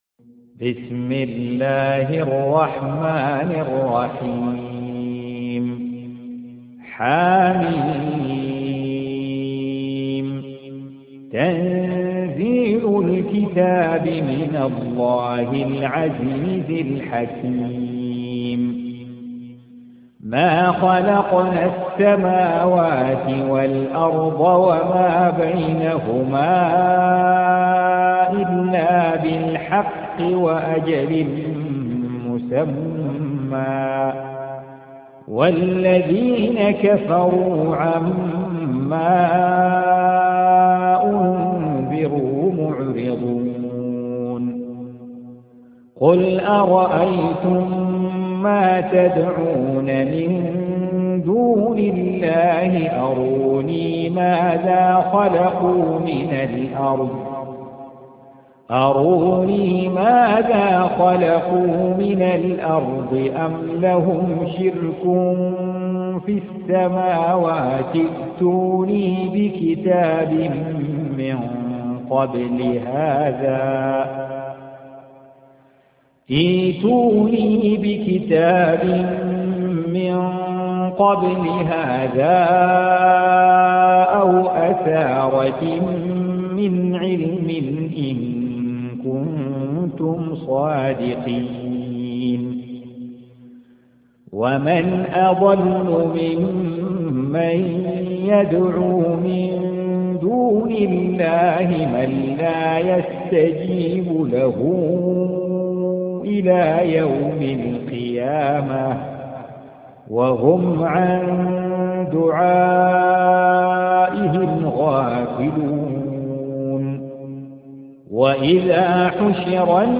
Audio Quran Tarteel Recitation
Surah Repeating تكرار السورة Download Surah حمّل السورة Reciting Murattalah Audio for 46. Surah Al-Ahq�f سورة الأحقاف N.B *Surah Includes Al-Basmalah Reciters Sequents تتابع التلاوات Reciters Repeats تكرار التلاوات